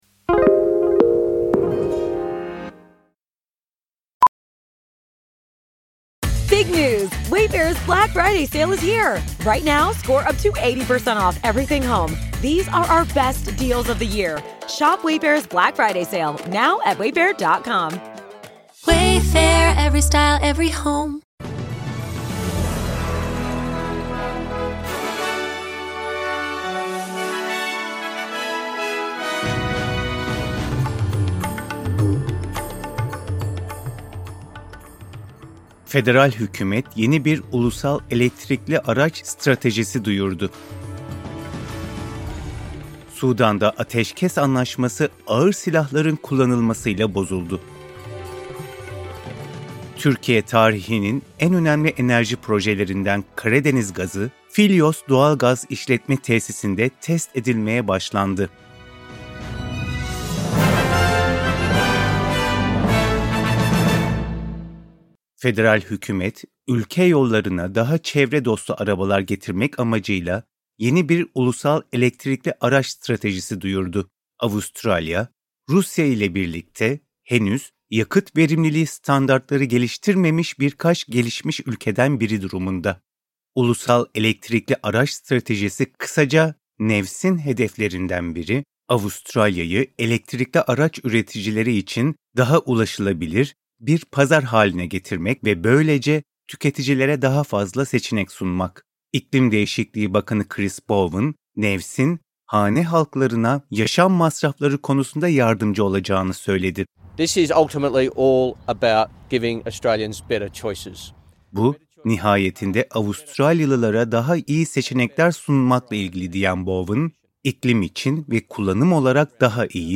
SBS Türkçe Haber Bülteni Source: SBS